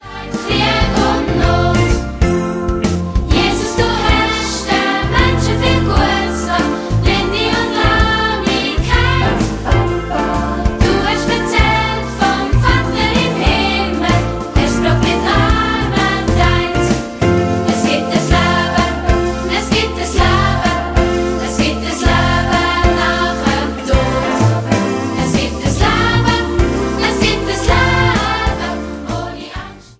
neue und alte Dialektsongs für Kinder